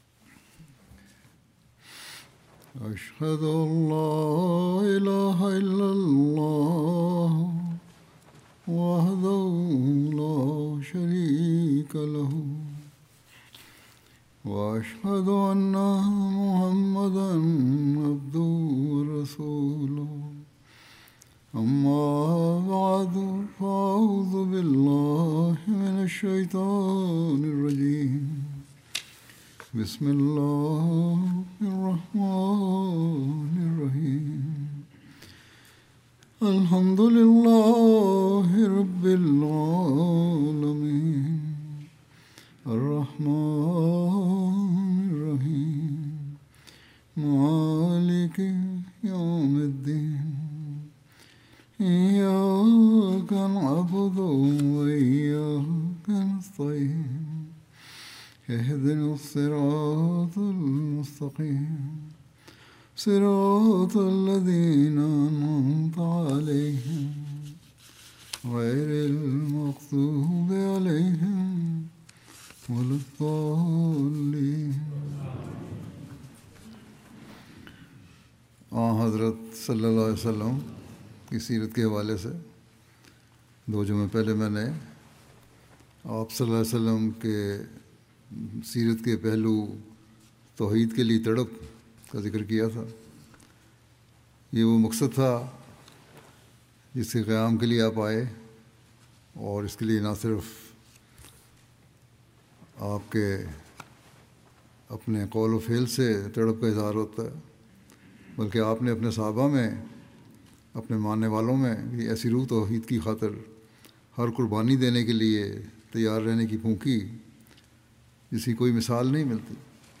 13 March 2026 The Prophet (sa) as the Guardian and Teacher of Tauheed Urdu Friday Sermon by Head of Ahmadiyya Muslim Community 50 min About Urdu Friday Sermon delivered by Khalifa-tul-Masih on March 13th, 2026 (audio)